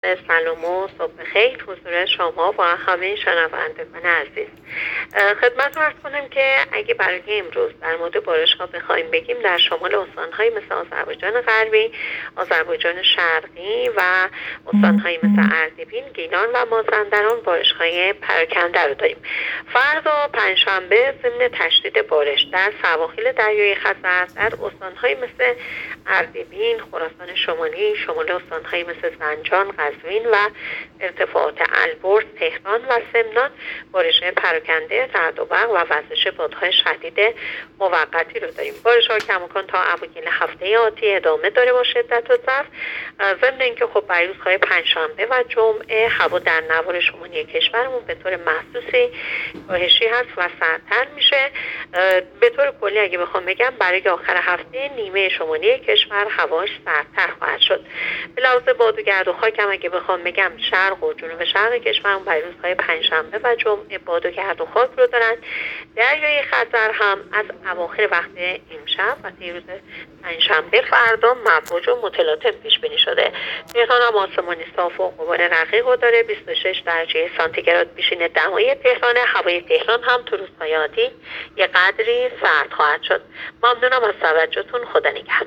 گزارش رادیو اینترنتی پایگاه‌ خبری از آخرین وضعیت آب‌وهوای هفتم آبان؛